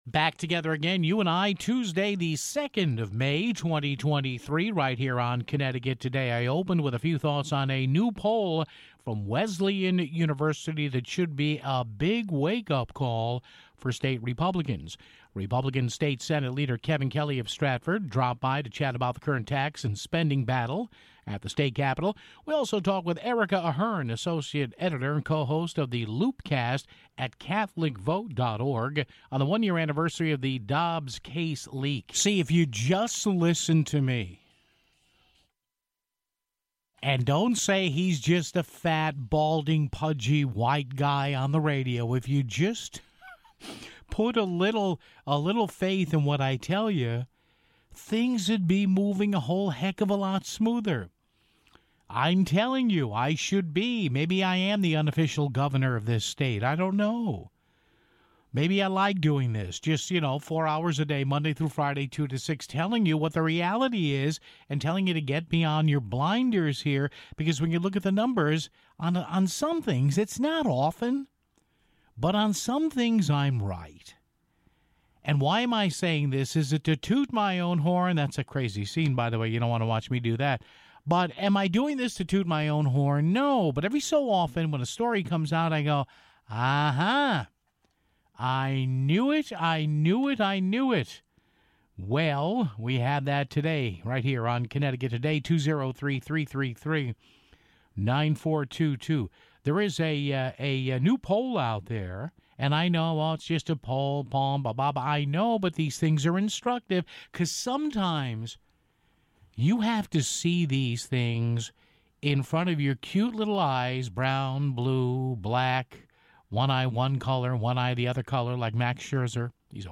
GOP State Senate Leader Kevin Kelly joined us to chat about the current tax and spending battle at the State Capitol (15:44).